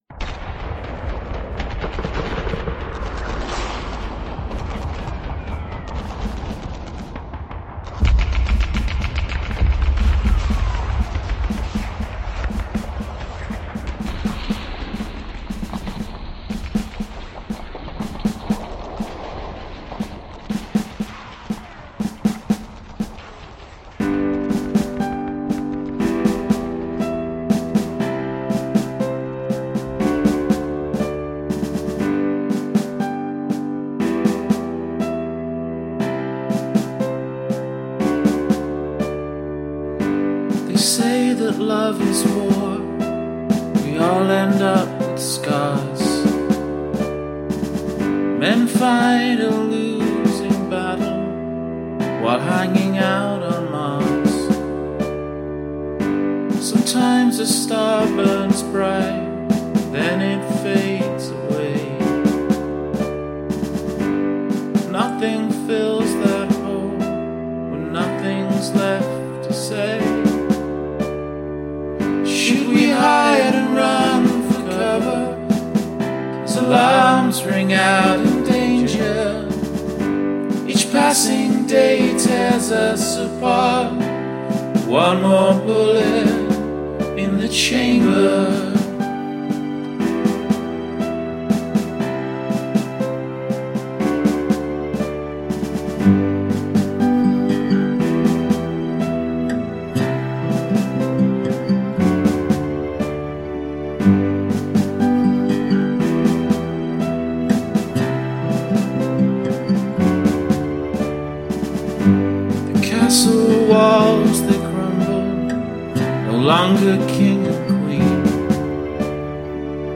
The song has a very calming and relaxing feeling to it.
The vocalist sounds like he almost has a British accent.
Wow I love the guitar solo, this is such a unique song.